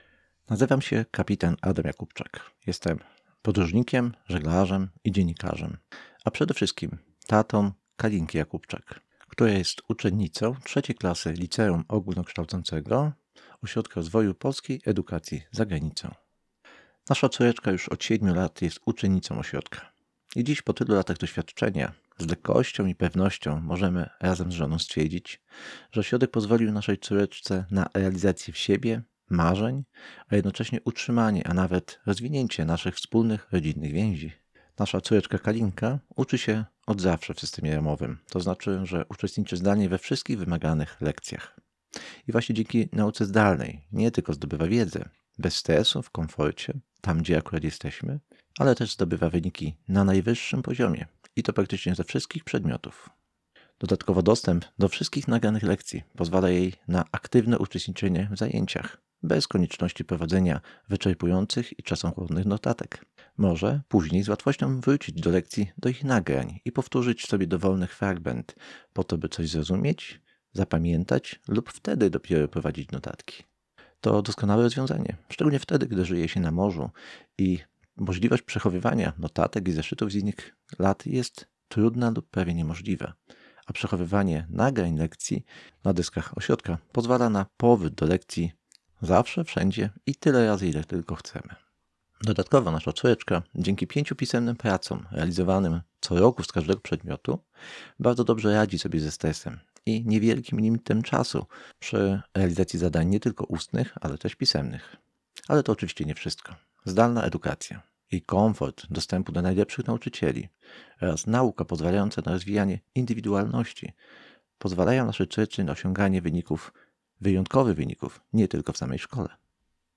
2. Rekomendacja rodzica uczennicy KNO.